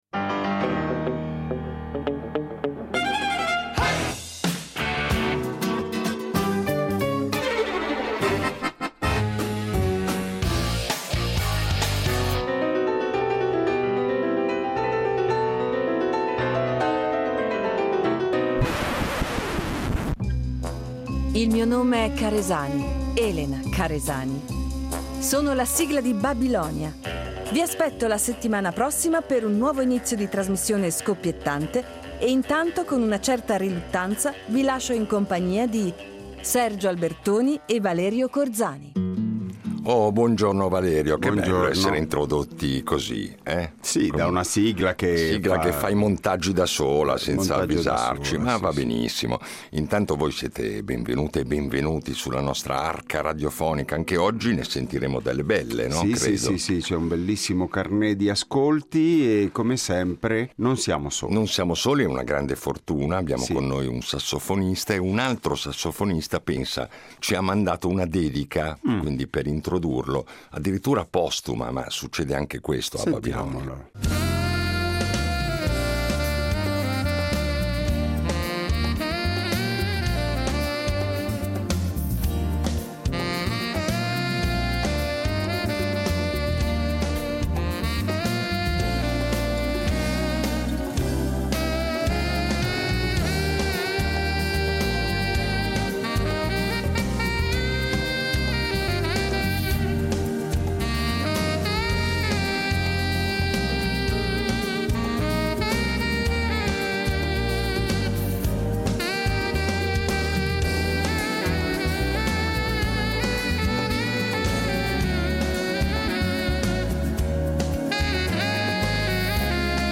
Il nostro ospite di oggi è un sassofonista, compositore e arrangiatore tra le voci più significative del jazz italiano contemporaneo.